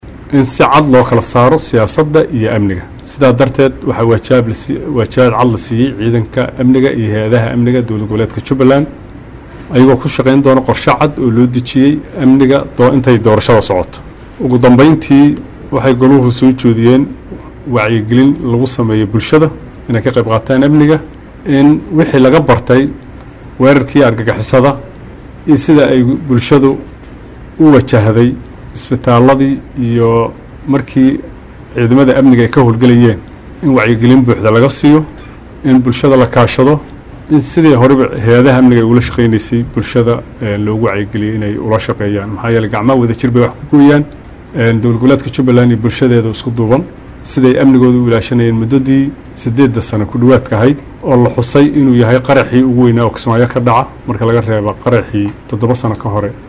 Kismaayo (Caasimada Online ) – Madaxweyne ku xigeenka mamulka Jubbaland Maxamuud Sayid Aadan oo shir jaraa’id u qabtay warbaahinta ayaa ka warbixiyey shir looga hadlayay amniga oo lagu qabtay magaalada Kismaayo ee xarunta gobolka Jubbada Hoose.